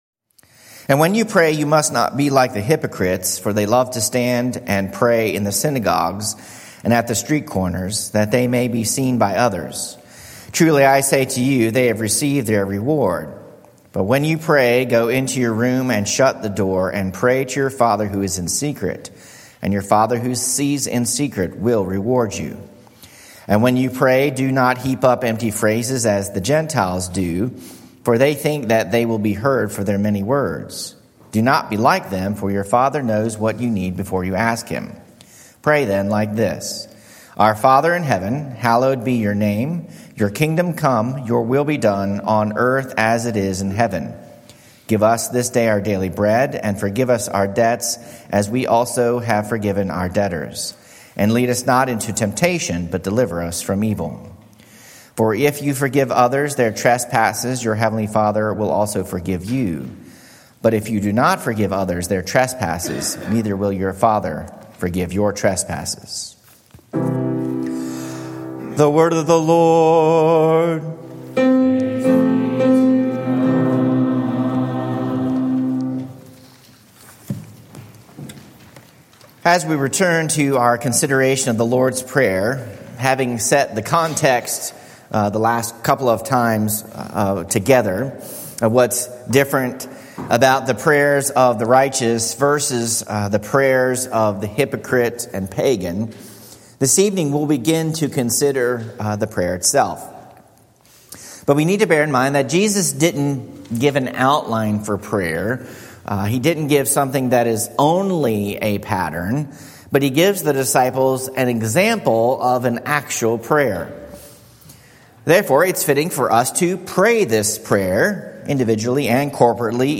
Series: Vespers Service Meditations